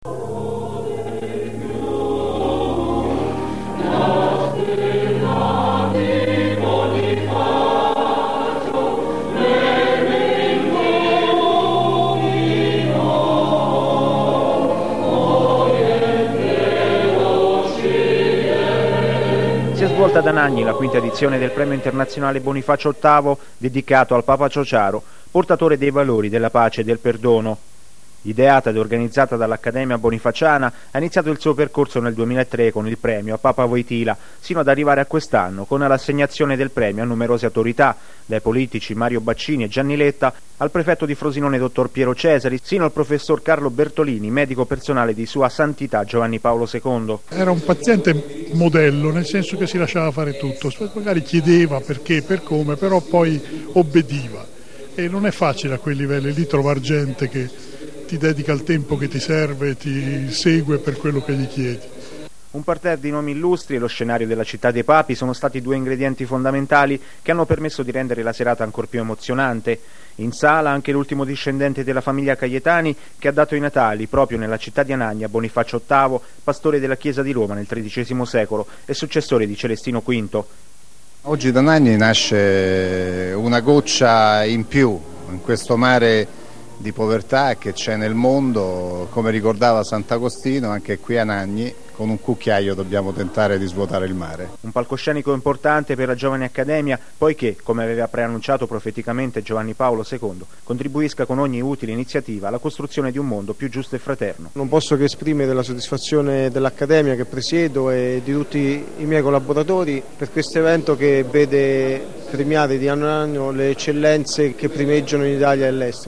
V� Premio Bonifacio VIII - Anagni 10.11.2007 Registrazione audio del Servizio su Lazio TV